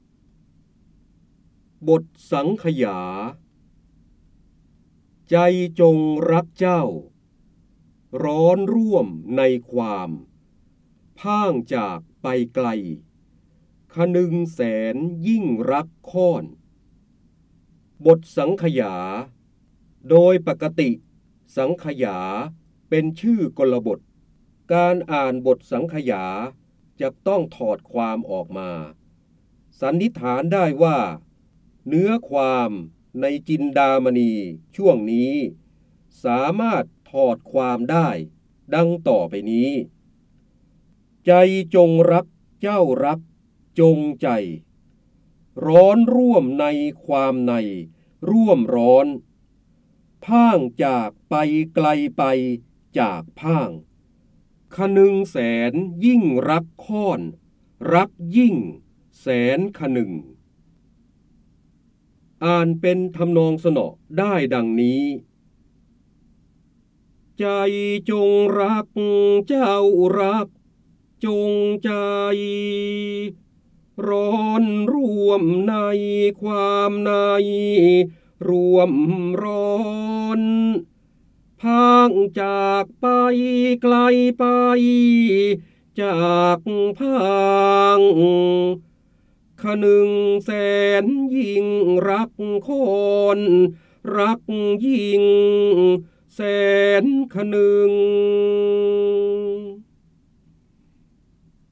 เสียงบรรยายจากหนังสือ จินดามณี (พระโหราธิบดี) บทสังขยา
คำสำคัญ : การอ่านออกเสียง, พระเจ้าบรมโกศ, ร้อยแก้ว, พระโหราธิบดี, ร้อยกรอง, จินดามณี